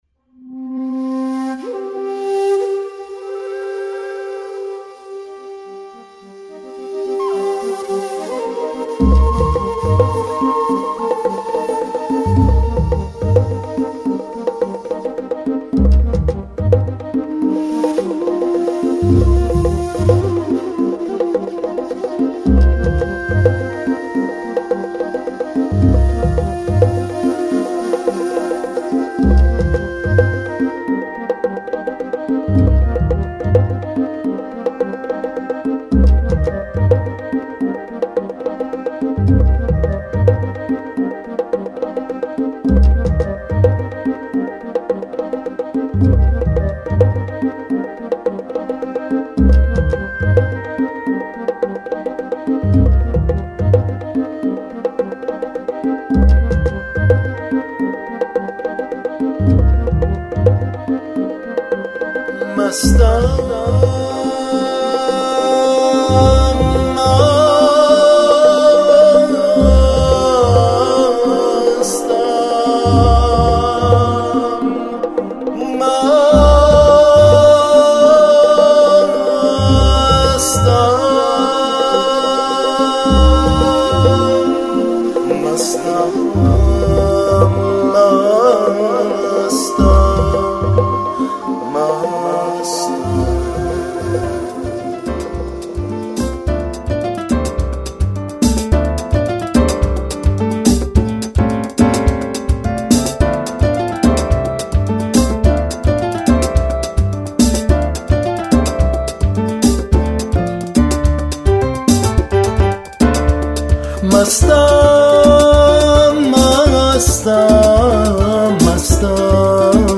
Genre: Blues